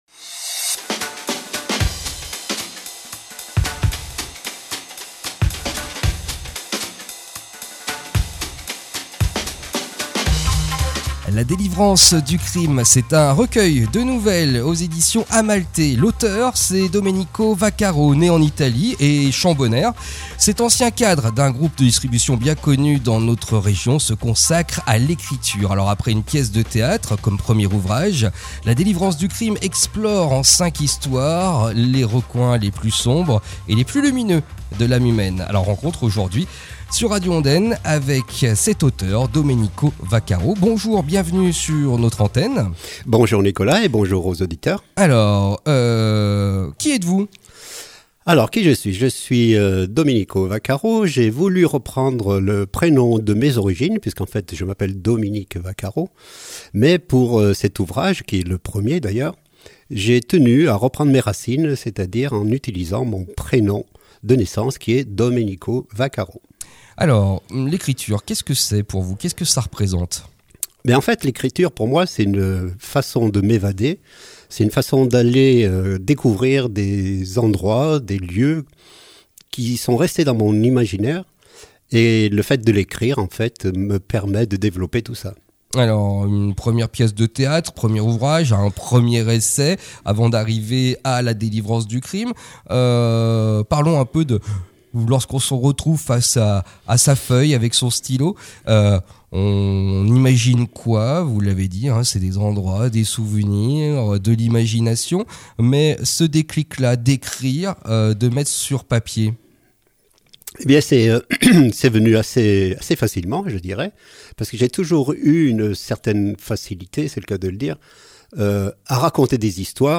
» La Délivrance du Crime », aux éditions Amalthée, est un recueil de 5 nouvelles qui explorent les recoins les plus sombres et les plus lumineux de l’âme humaine. Rencontre à écouter sur Radio Ondaine, aujourd’hui à 11h30.